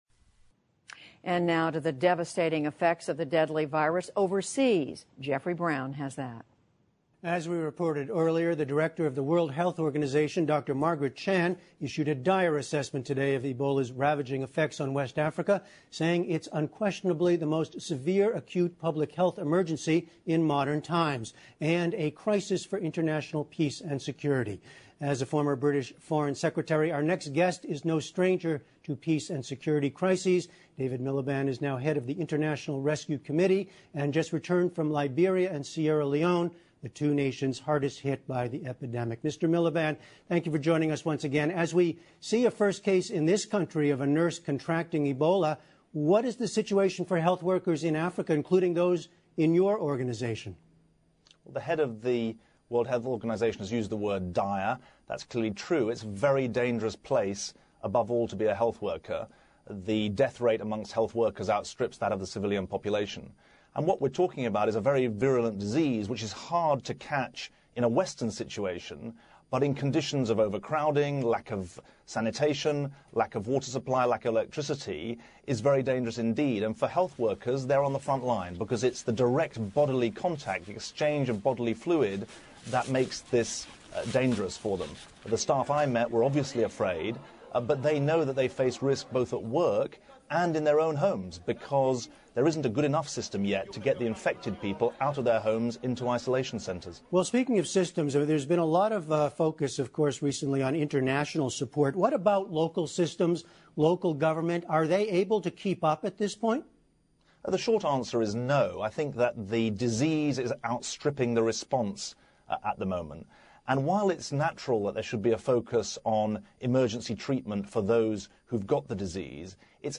PBS高端访谈:利比里亚和塞拉利昂封锁疫区 听力文件下载—在线英语听力室